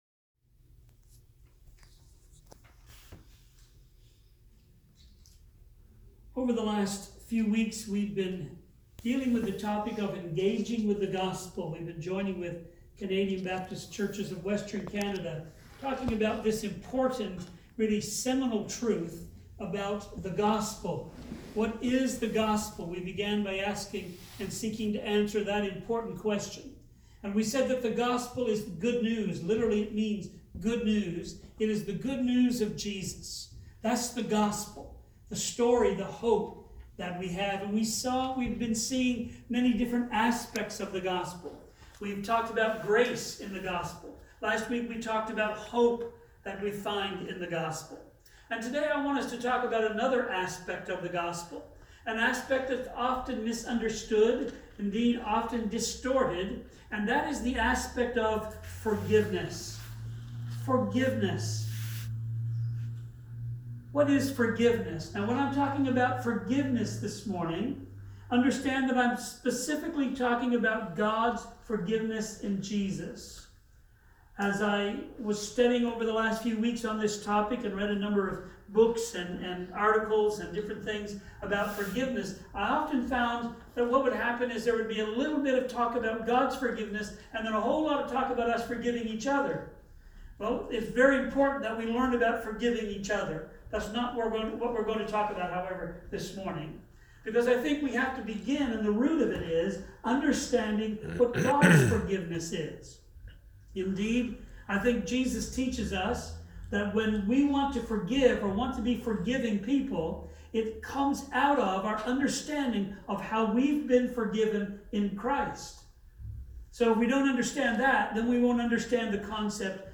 Sermon-Nov-3.mp3